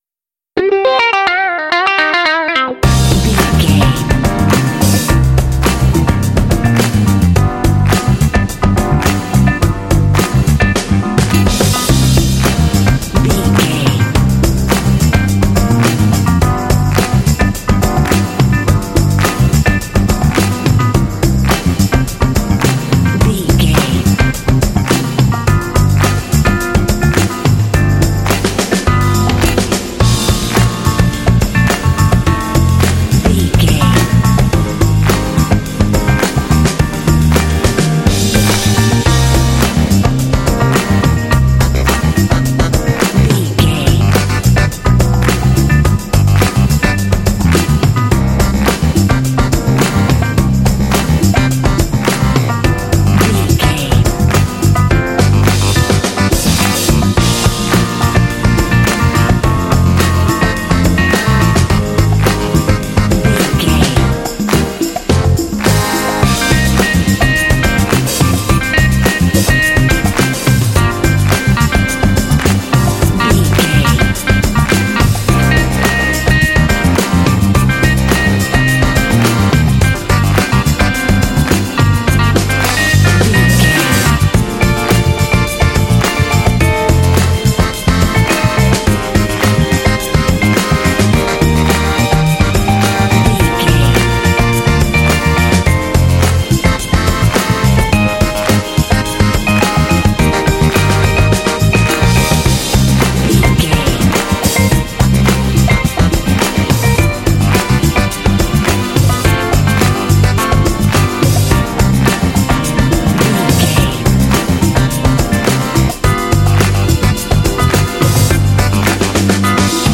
This funky track is ideal for kids and sports games.
Uplifting
Aeolian/Minor
funky
groovy
driving
energetic
lively
bass guitar
drums
percussion
electric piano
saxophone
electric guitar
strings
Funk
alternative funk